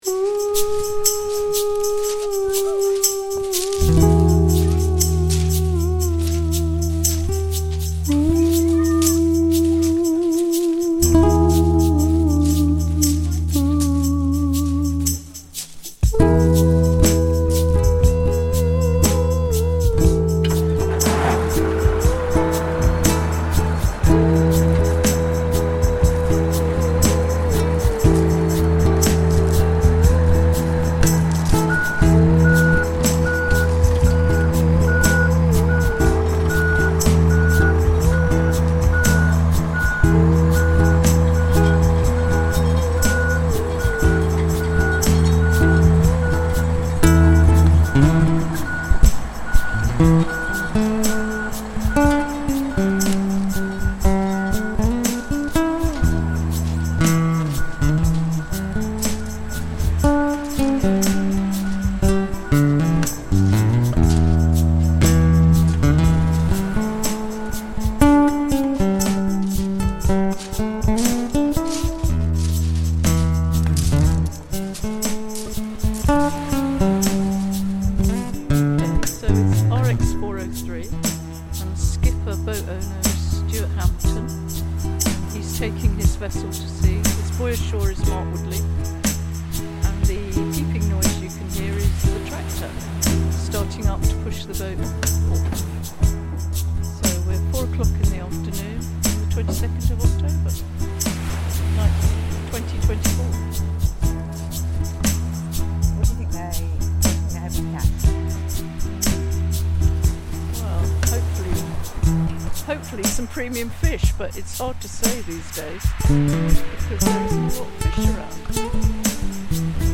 Hastings boat launch reimagined